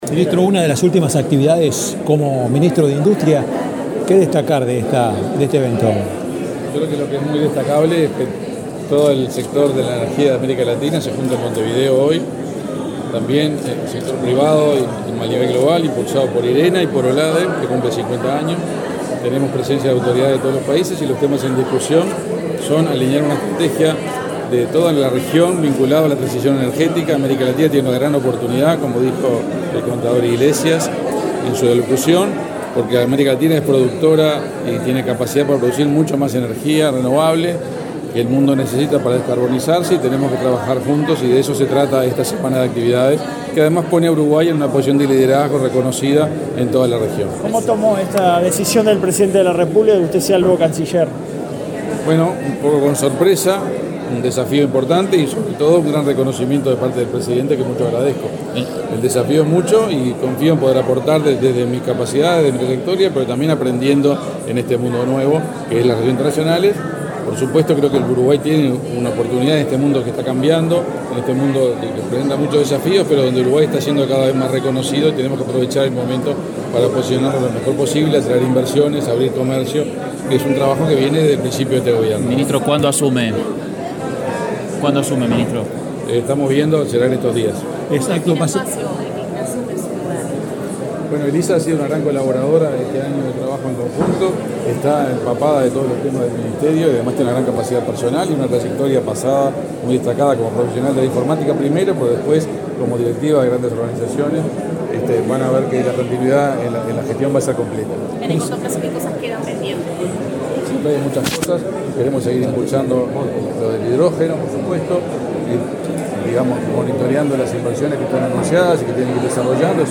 Declaraciones del ministro de Industria, Omar Paganini
Luego, el ministro dialogó con la prensa.